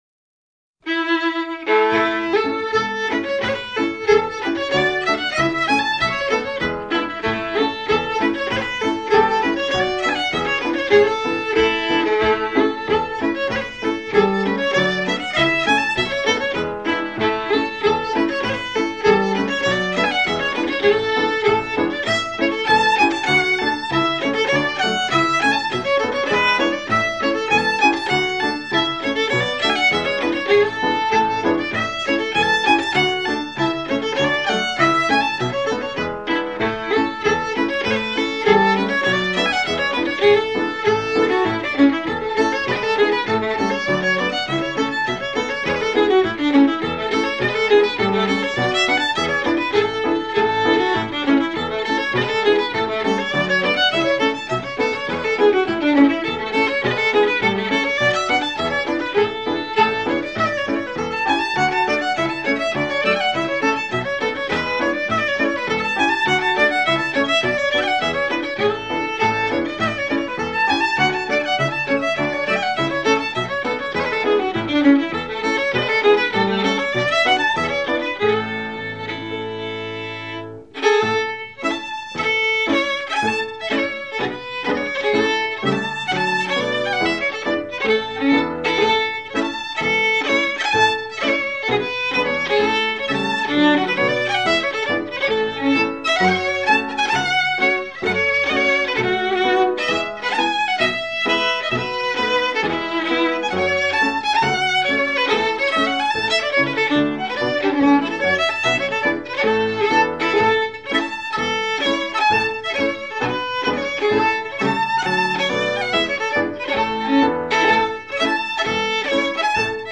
The Strathspey